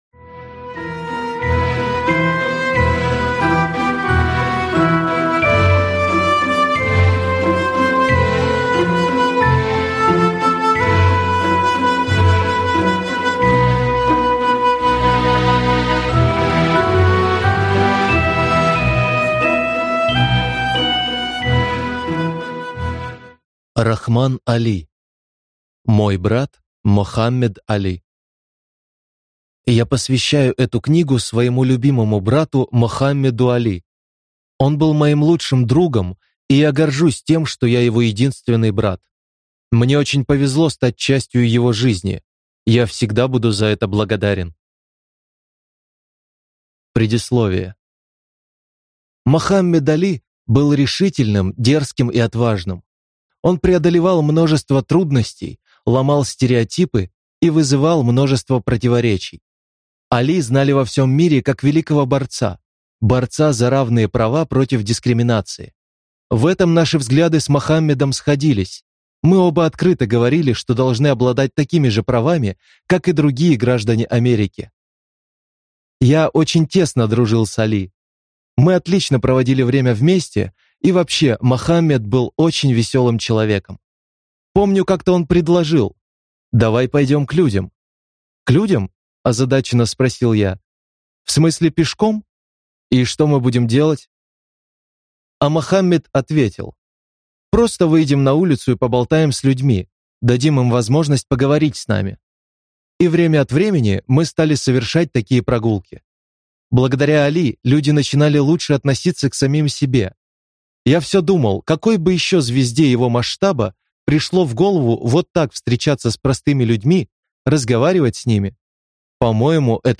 Аудиокнига Мой брат Мохаммед Али | Библиотека аудиокниг